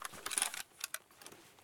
ak74
draw.ogg